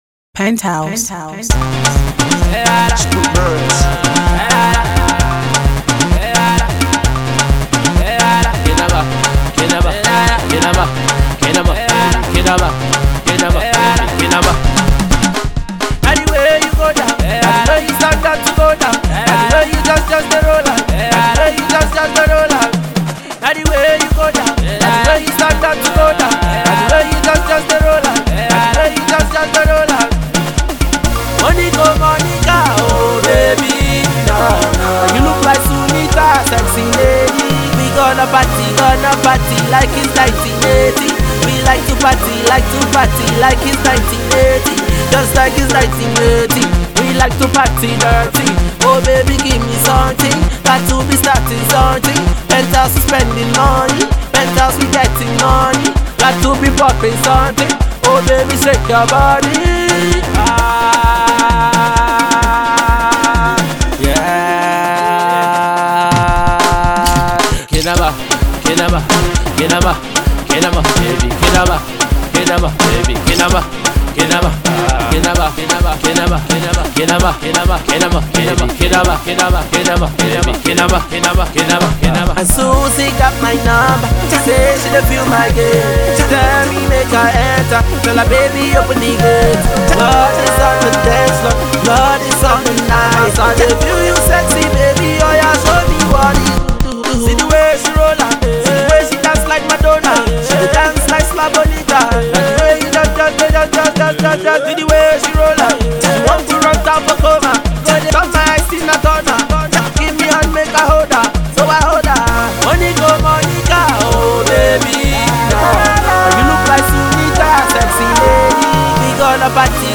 Dancefloor candy.